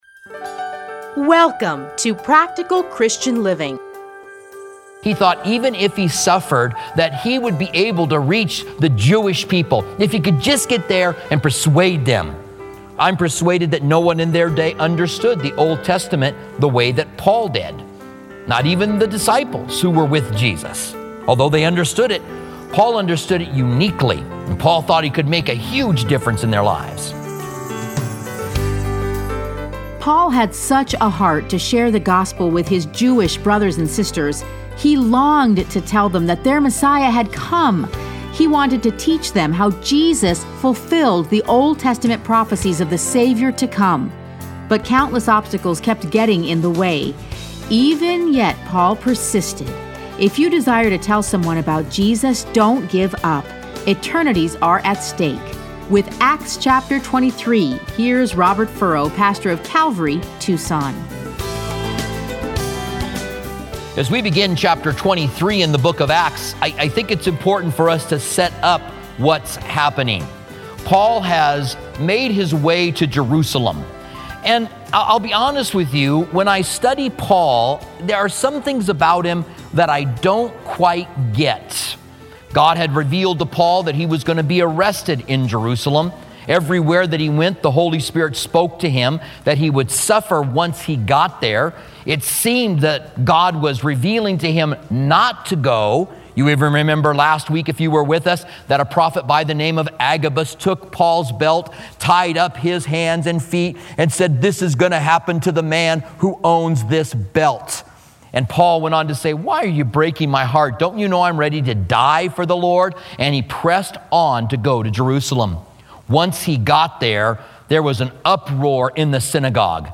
Listen to a teaching from Acts 23.